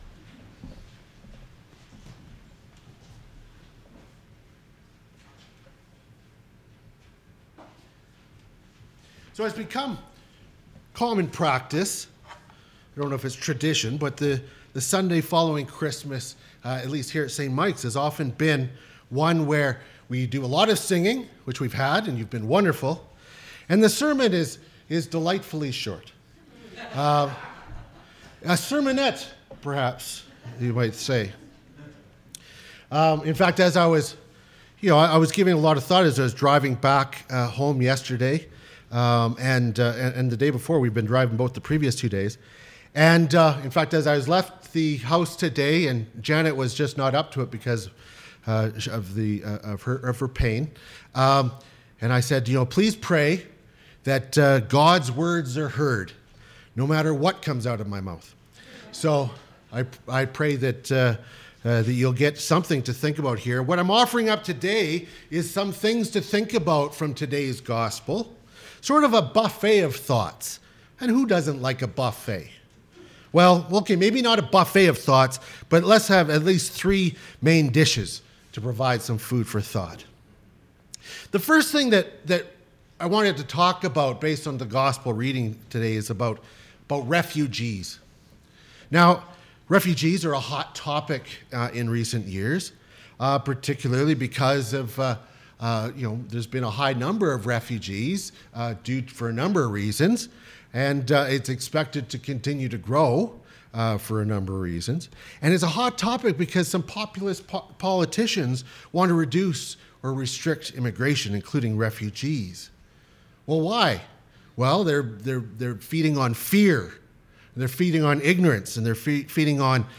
Sermons | St. Michael Anglican Church